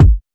DrKick16.wav